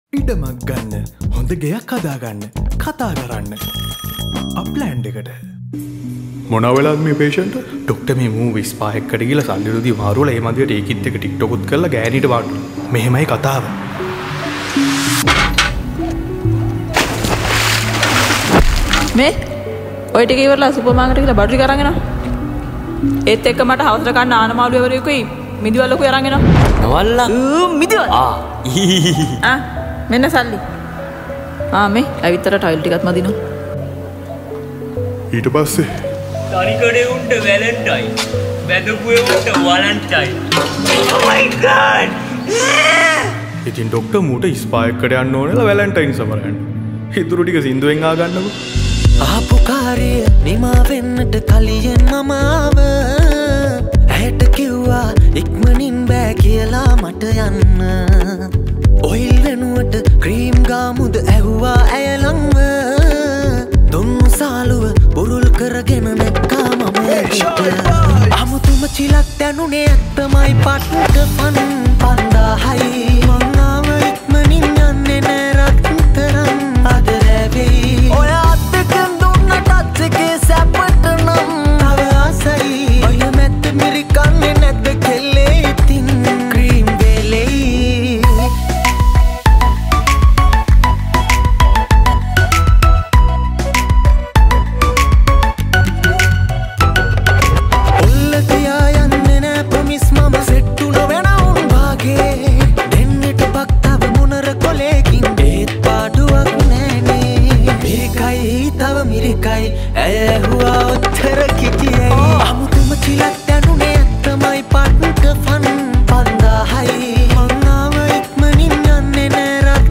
Parody Song